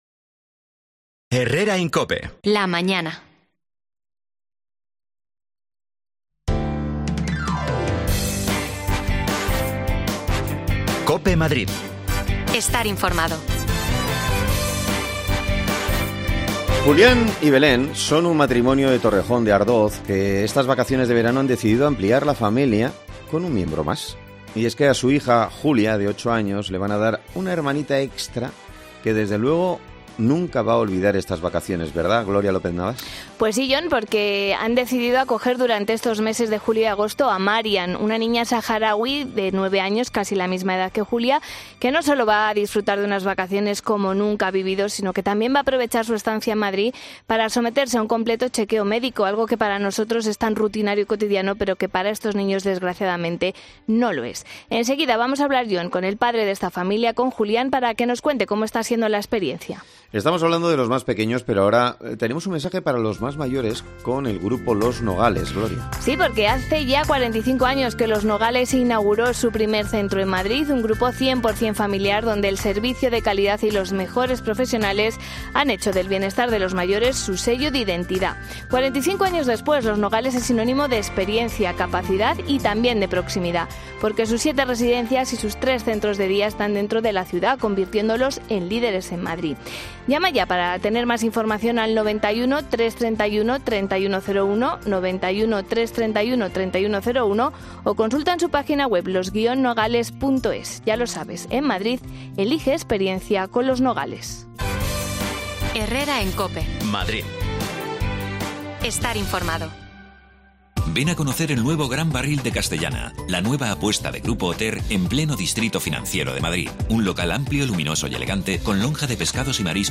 Hablamos con una de ellas
Las desconexiones locales de Madrid son espacios de 10 minutos de duración que se emiten en COPE, de lunes a viernes.